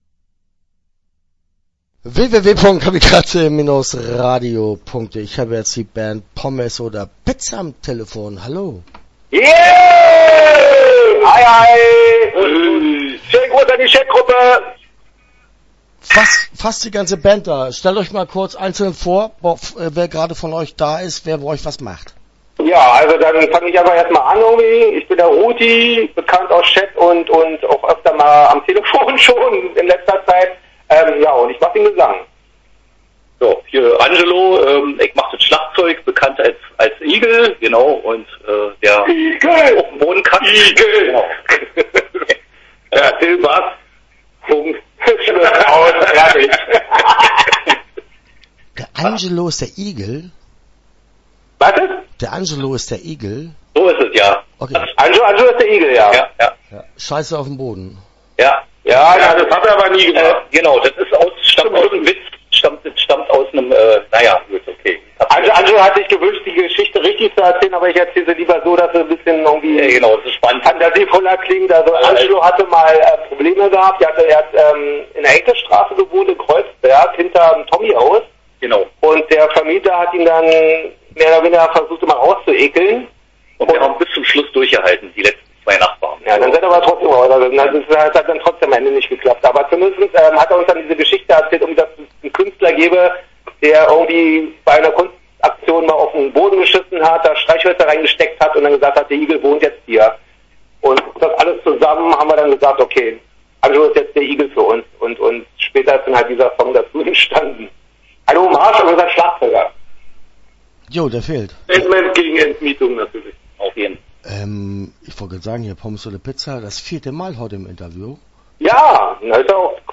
Pommes Oder Pizza - Interview Teil 1 (12:22)